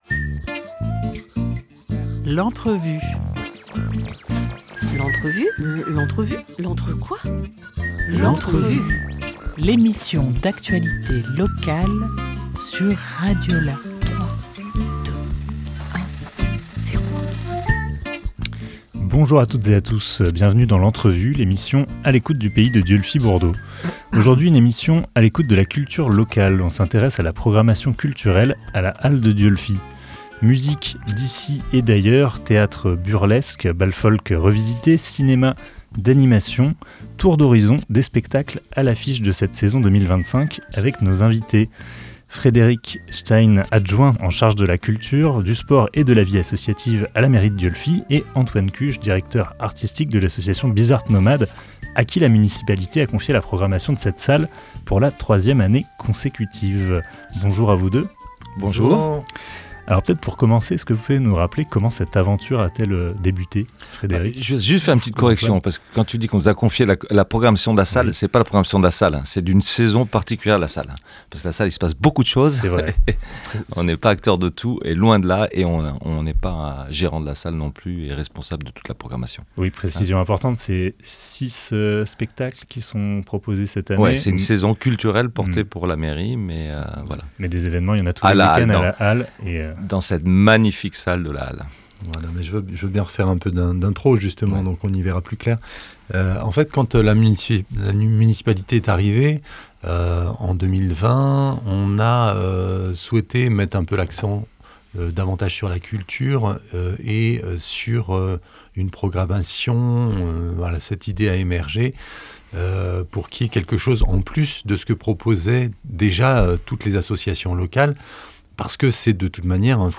14 janvier 2025 11:25 | Interview